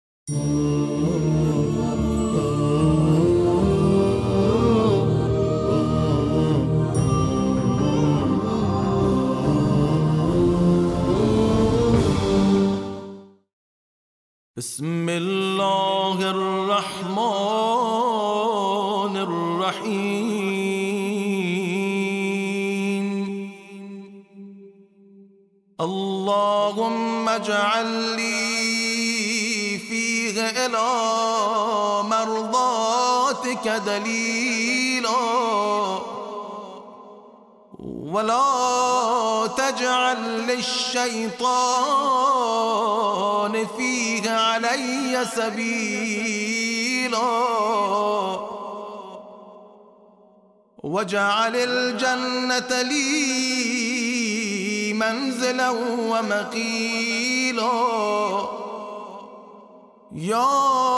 د روژې د مبارکې میاشتې د یویشتمې (۲۱)ورځې په زړه پورې لنډه دعا